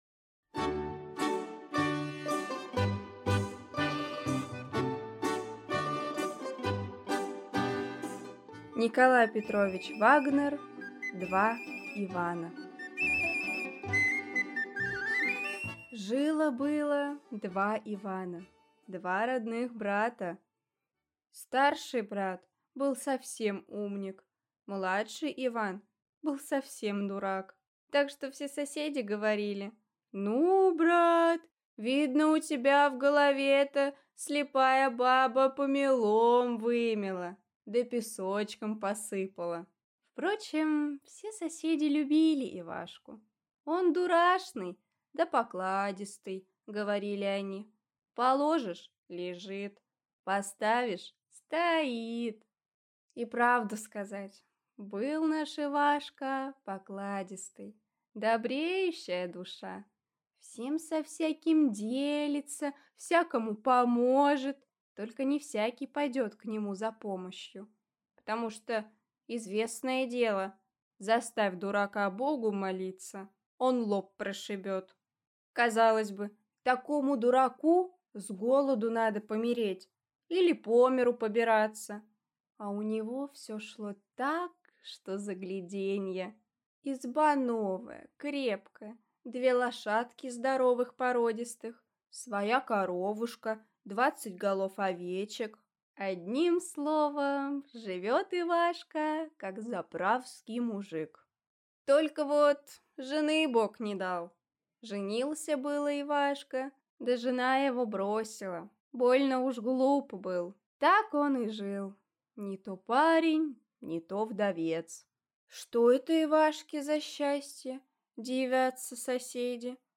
Аудиокнига Два Ивана | Библиотека аудиокниг